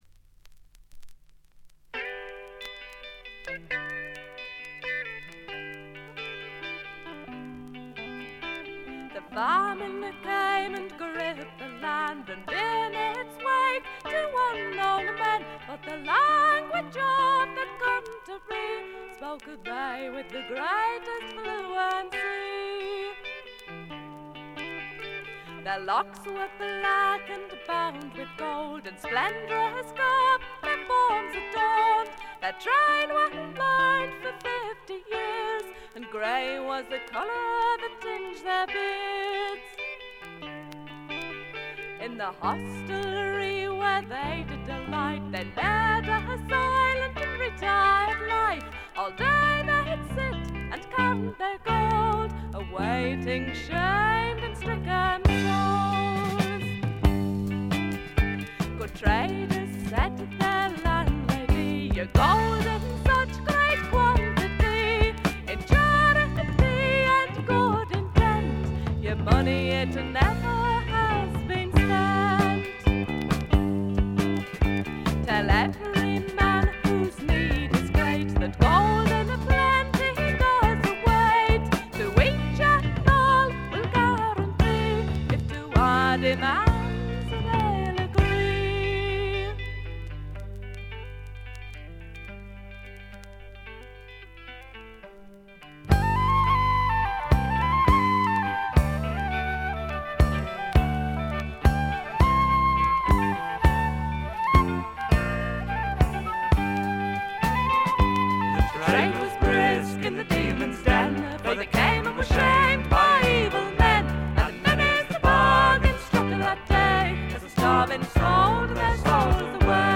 静音部で軽微なチリプチが聴かれますが鑑賞に影響するようなノイズはありません。
ドラムとベースがびしばし決まるウルトラグレートなフォーク・ロックです。
試聴曲は現品からの取り込み音源です。